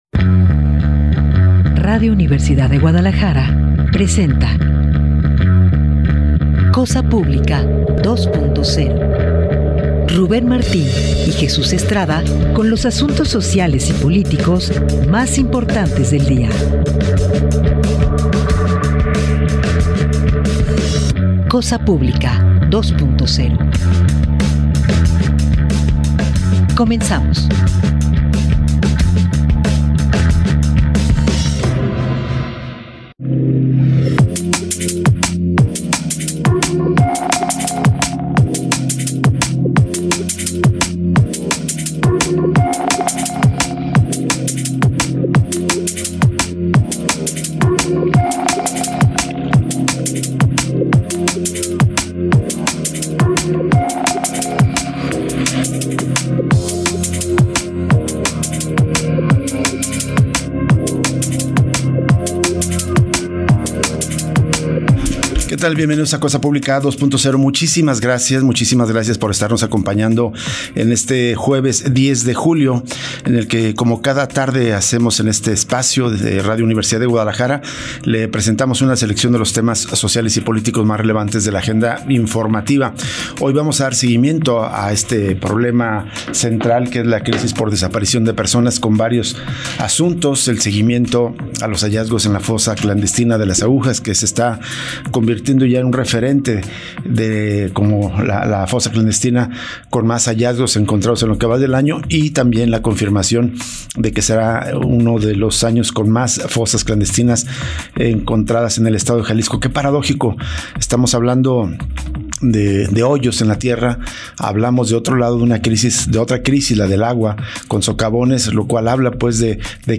Hoy con: Tonatzin Cárdenas, diputada local de Futuro.